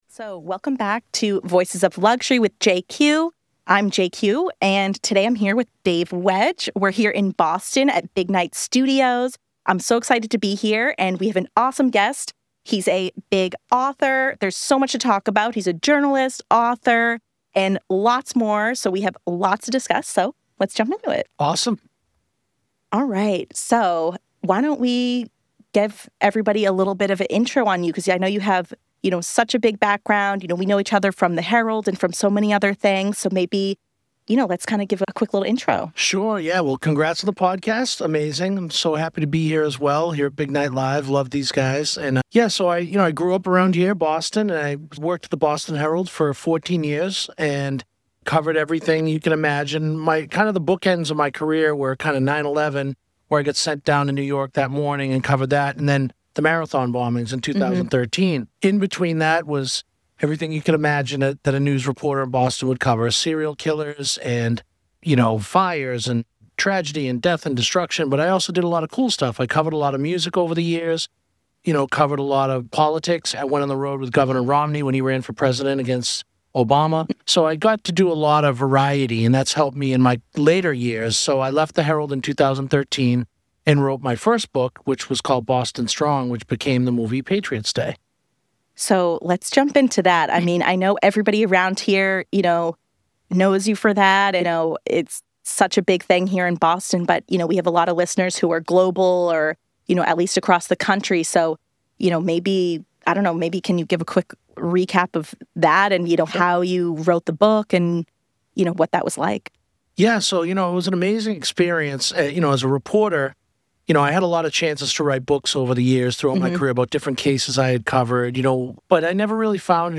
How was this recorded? live from Big Night Studios in Boston.